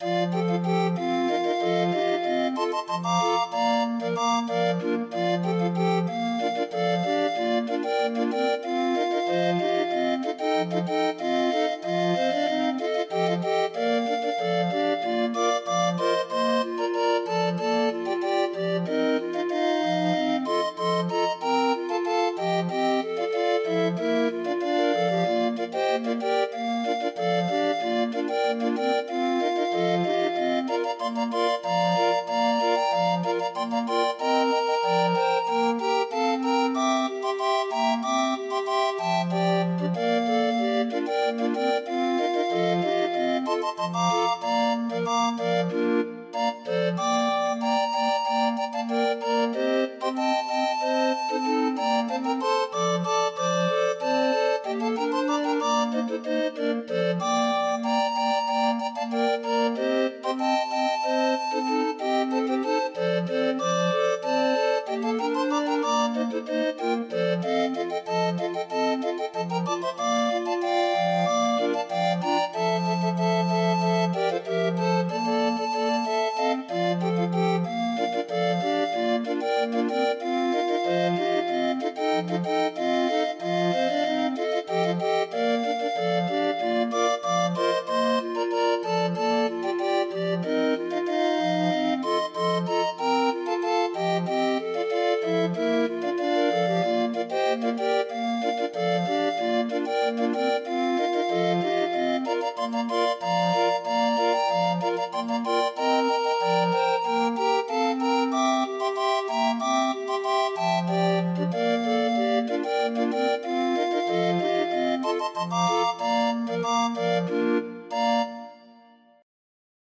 Demo of 20 note MIDI file